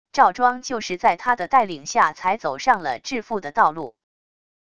赵庄就是在他的带领下才走上了致富的道路wav音频生成系统WAV Audio Player